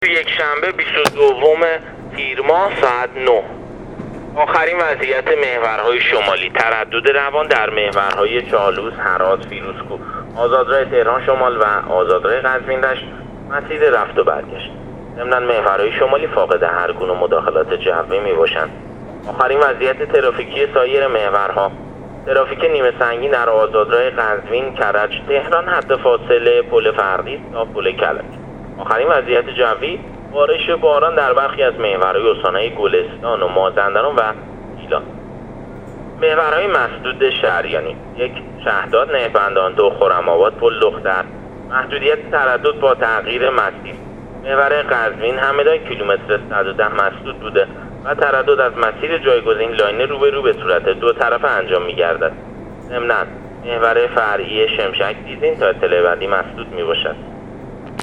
گزارش رادیواینترنتی از وضعیت ترافیکی جاده‌ها تا ساعت ۹ یکشنبه۲۲ تیر